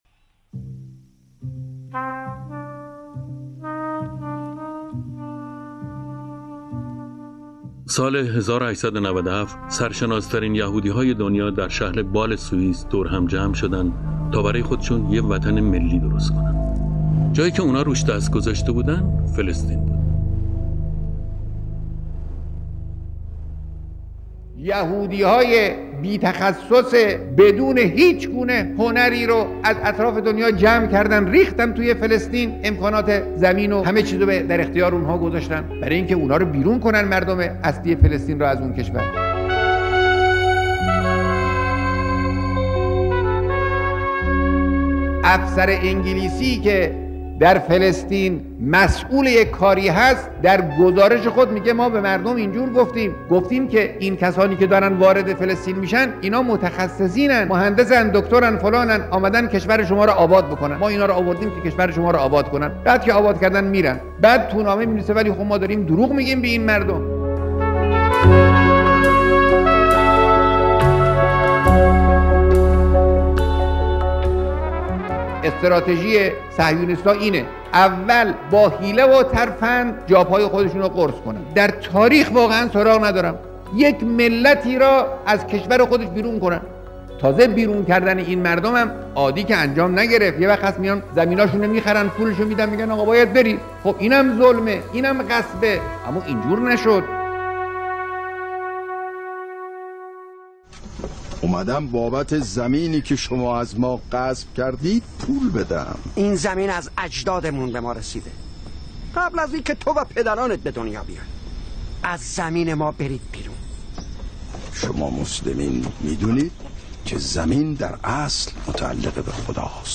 کد خبر: ۳۶۸۳۷۲ تعداد نظرات: ۱ نظر تاریخ انتشار: ۱۱ تير ۱۳۹۵ - ۱۲:۴۹ صفحه نخست » سیاسی ‍‍‍ پ پ ‍‍‍ روایت جنایتی بی‌سابقه در تاریخ از زبان رهبر انقلاب صهیونیستها چگونه اراضی فلسطین را تصرف کردتد؟